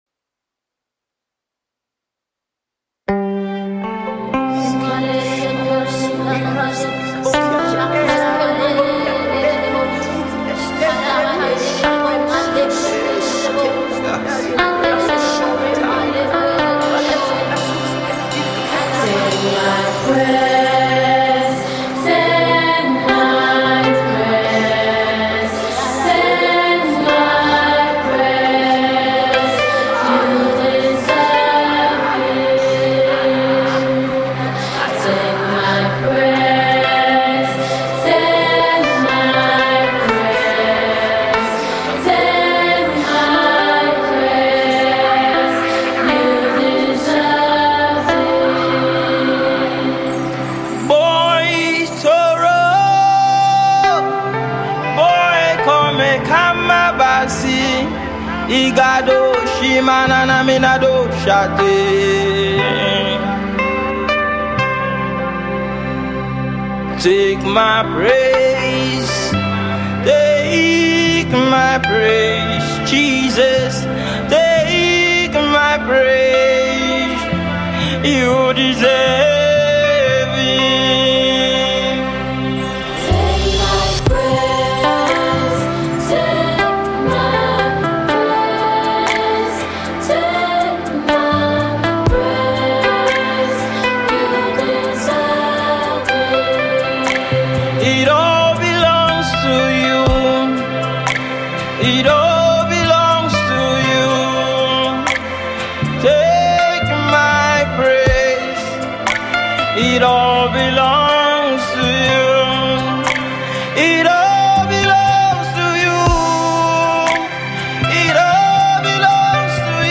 The ever passionate Gospel singer